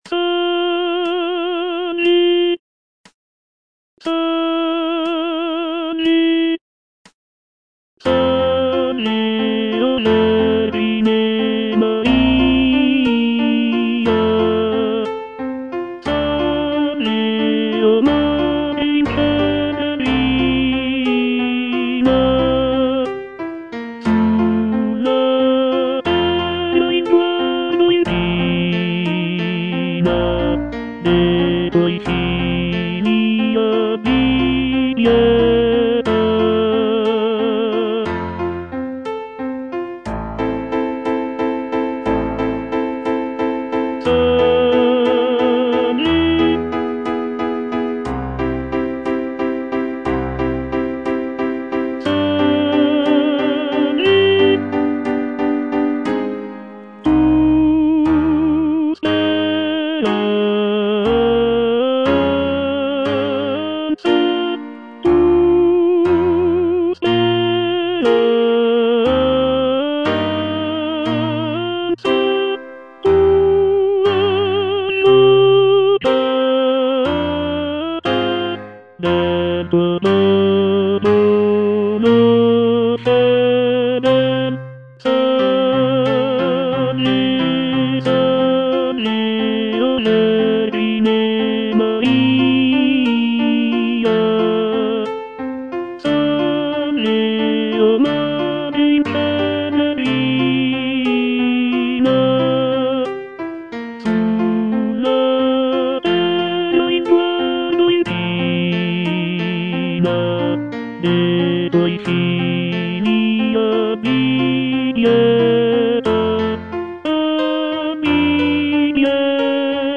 G. ROSSINI - SALVE O VERGINE MARIA (EDITION 2) Tenor (Voice with metronome) Ads stop: auto-stop